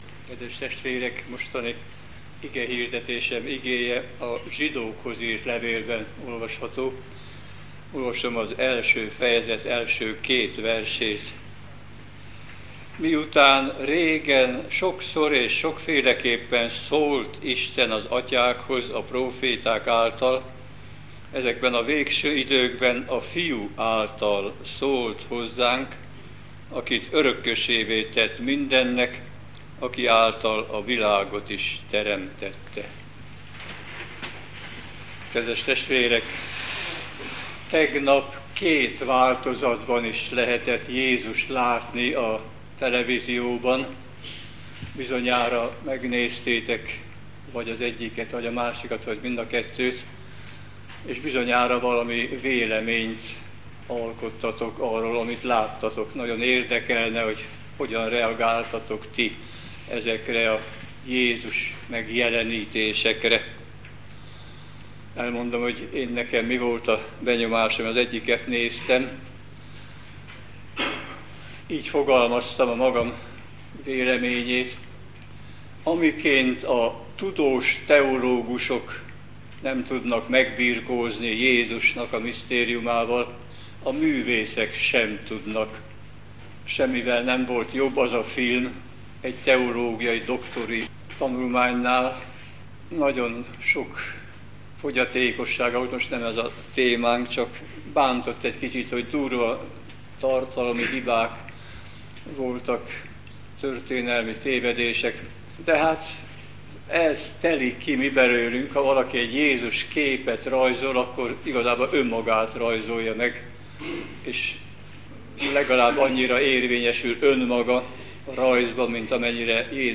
igehirdetései